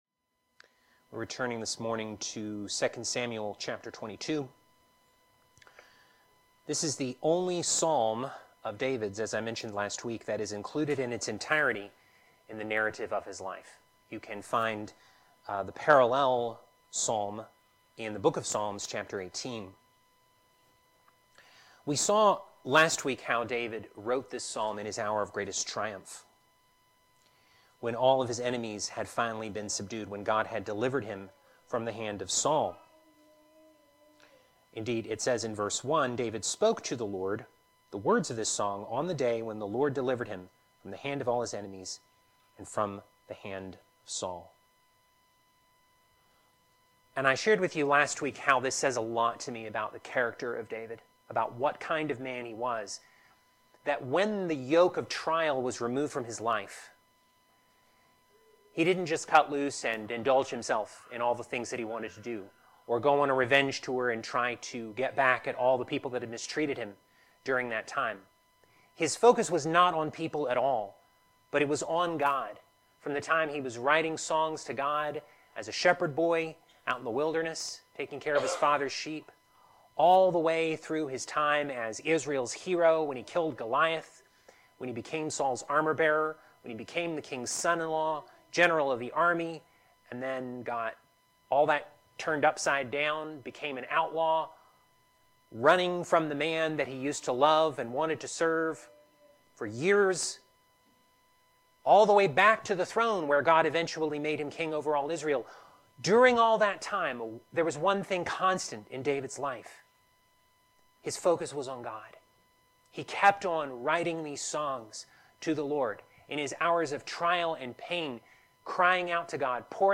Teaching For March 2, 2025